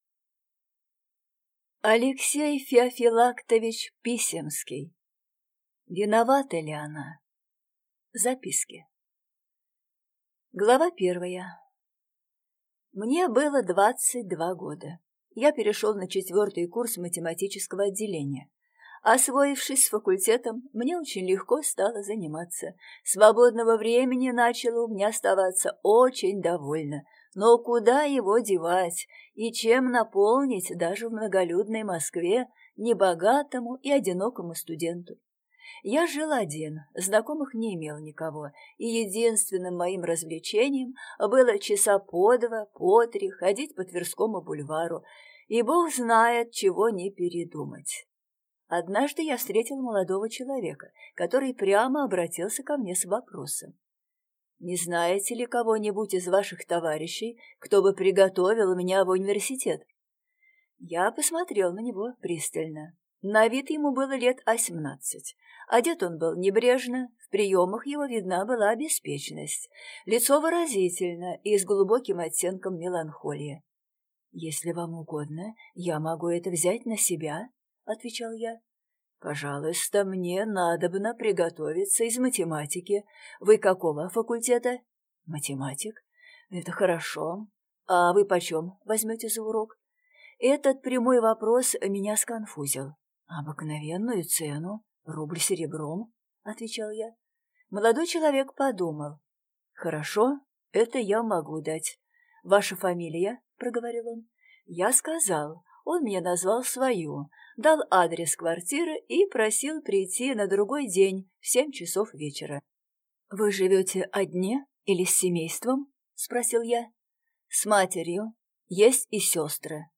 Аудиокнига Виновата ли она?